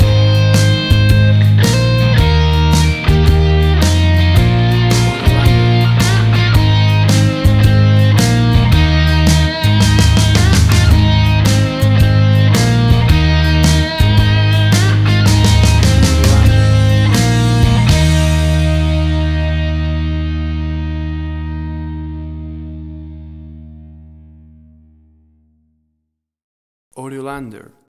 A big and powerful rocking version
Tempo (BPM): 110